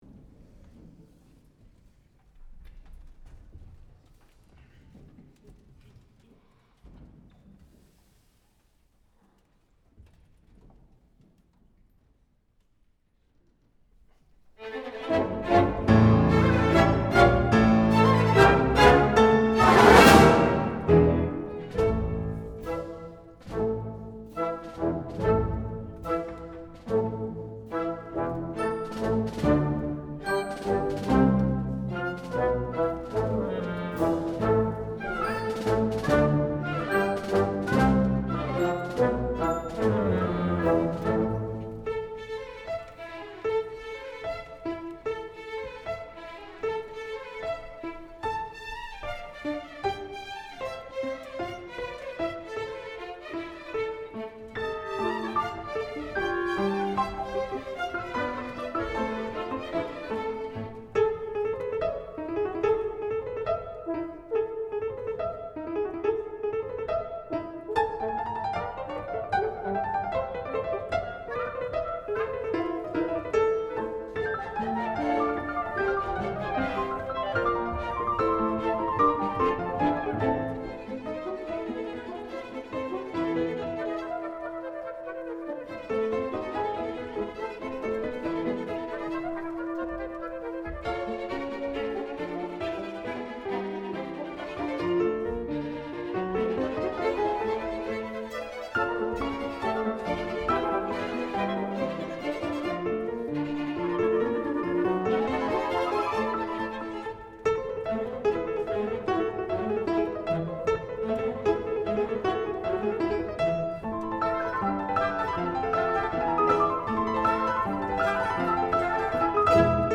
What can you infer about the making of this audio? recorded in 2018, Teatro di Budrio (Bologna)